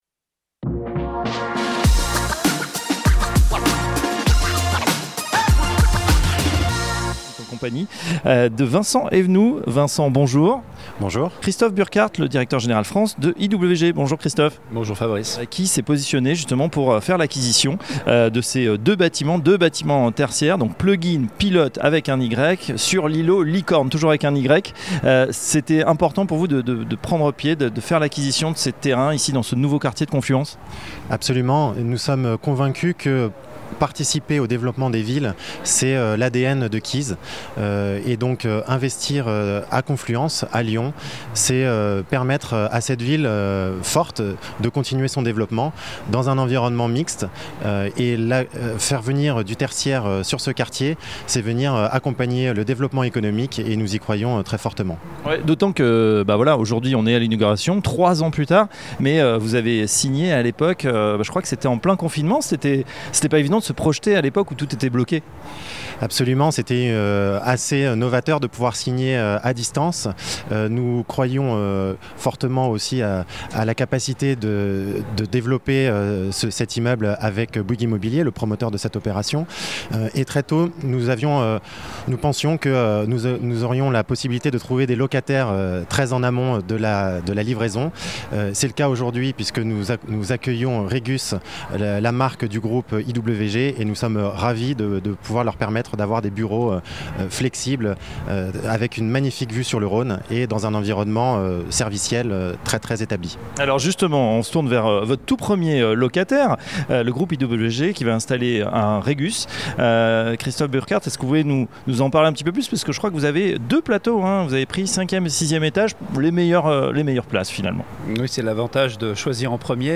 Table Ronde 6 - OPCI & SCPI - Partie 2 - Salon de l'immobilier de Paris Octobre 2018